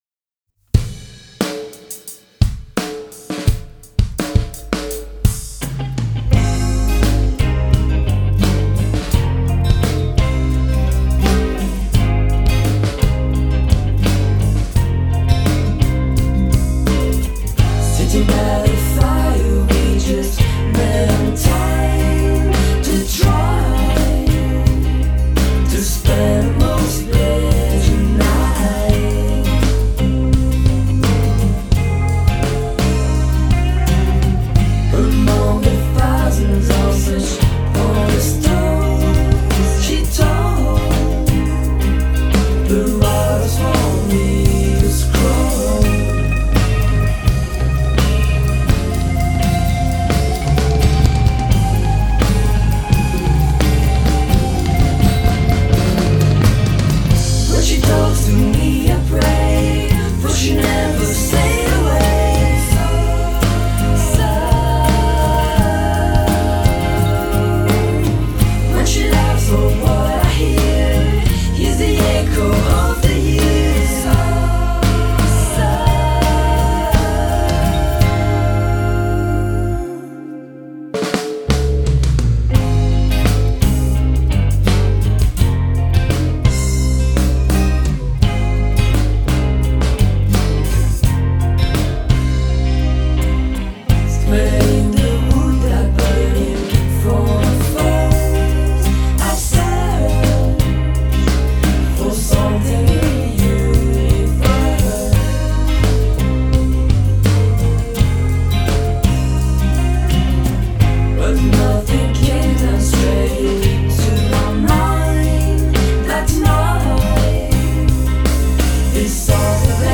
Indie-Rock > Garage > Power-Pop